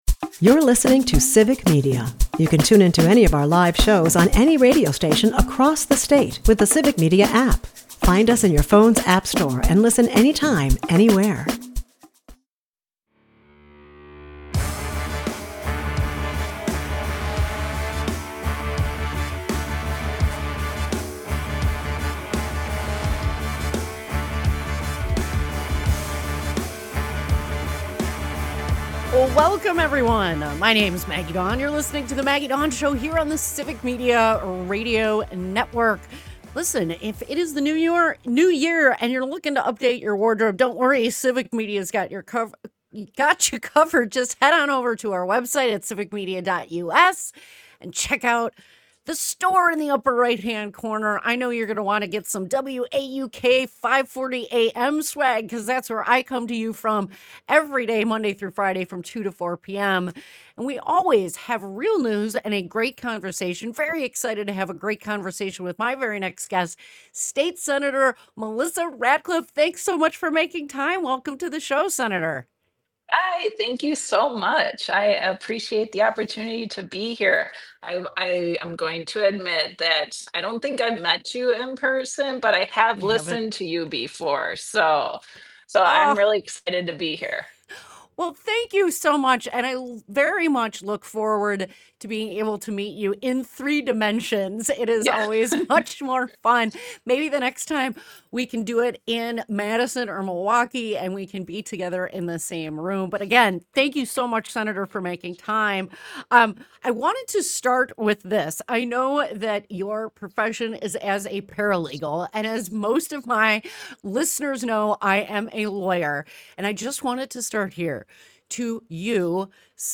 Good Mood Guests: Sen. Melissa Ratcliff 2/5/2025 Listen Share This hour